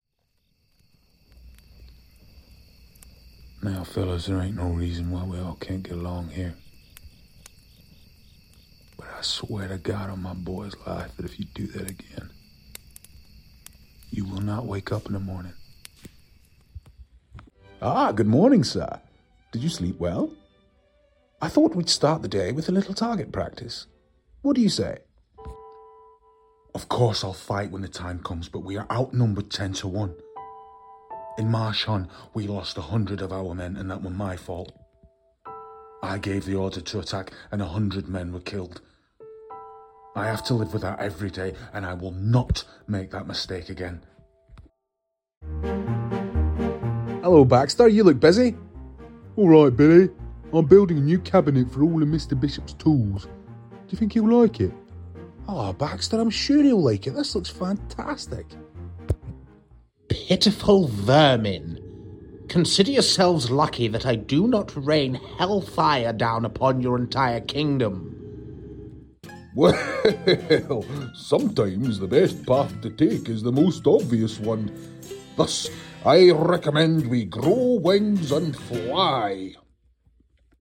- Voice quality: Warm, Strong . Voice character: Mysterious.
ACCENTS: Native: Central Scottish. High standard: American-Standard, Manchester, RP.
Gaming-Animation+VO+reel.mp3